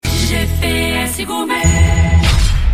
gps_medium_quality.mp3